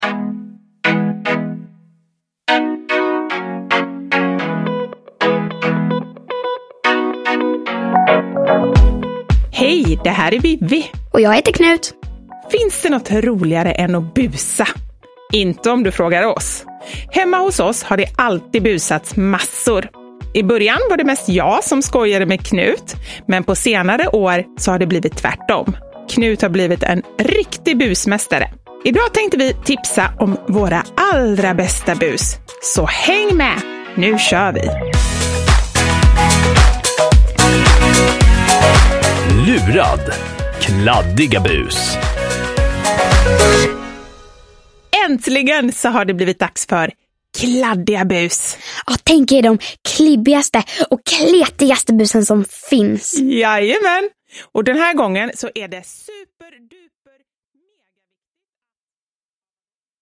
Lurad! Kladdiga bus – Ljudbok – Laddas ner